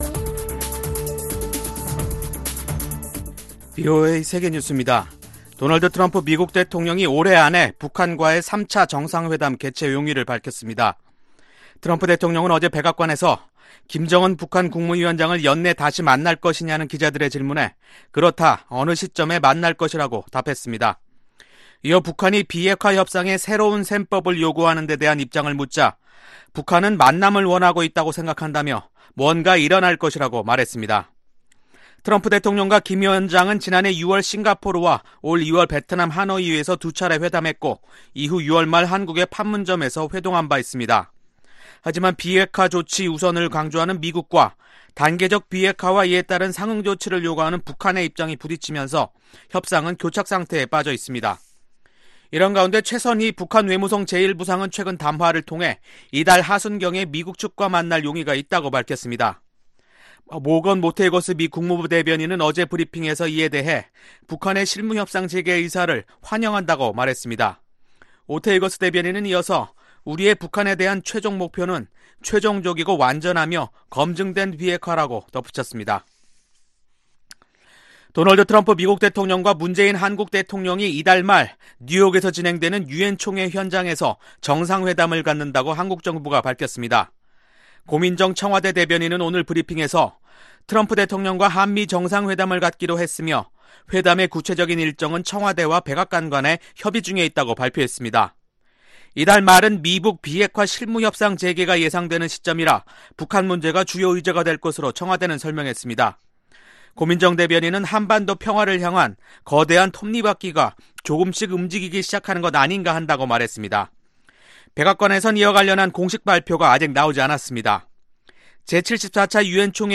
VOA 한국어 간판 뉴스 프로그램 '뉴스 투데이', 2019년 9월 13일 3부 방송입니다. 미국 국무부는 북한의 9월 하순 만남 제안에 대해 환영 입장을 밝혔고, 폼페오 국무장관과 비건 대북 특별대표는 협상을 원하는 트럼프 대통령의 희망을 실현 할 수 있을 것이라고 밝혔습니다. 트럼프 대통령이 올해 안에 김정은 북한 국무위원장과 다시 만날 의향이 있다고 밝혔습니다. 트럼프 대통령과 문재인 한국 대통령이 이달 말 열리는 유엔총회에서 정상회담을 갖습니다.